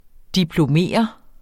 Udtale [ diploˈmeˀʌ ]